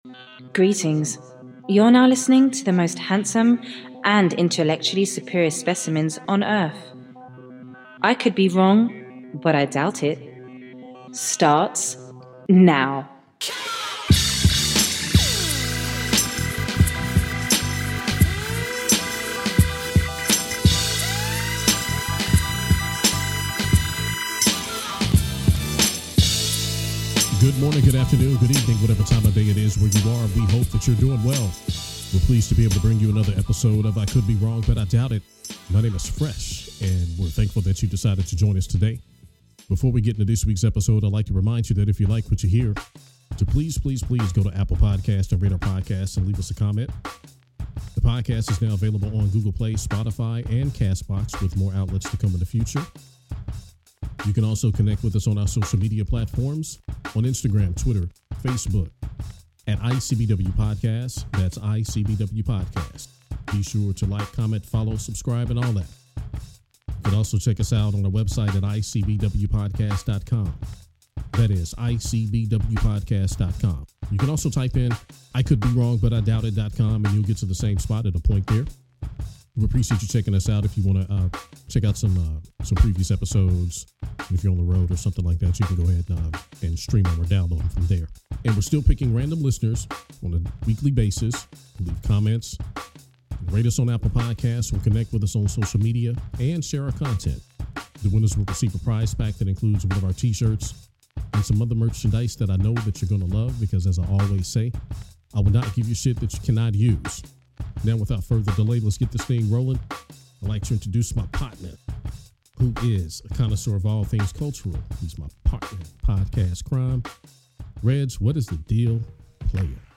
Episode 13 finds the duo speaking passionately about Colin Kaepernick, Nike, The NFL, the tragic, bizarre shooting of Botham Jean, and Serena Williams. There is lots of energy and raw emotion in this episode.